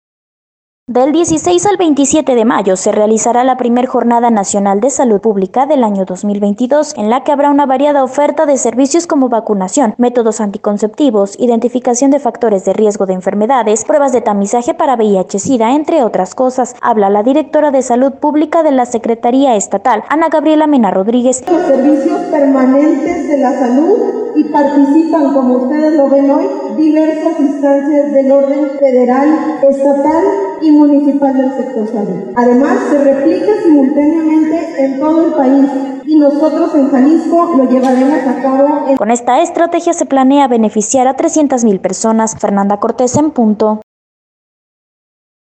Del 16 al 27 de mayo, se realizará la Primer Jornada Nacional de Salud Pública, 2022 en la que habrá una variada oferta de servicios cómo vacunación, métodos anticonceptivos, identificación de factores de riesgo de enfermedades, pruebas de tamizaje para VIH sida, entre otras cosas. Habla la directora de Salud Pública de la Secretaría Estatal, Ana Gabriela Mena Rodríguez: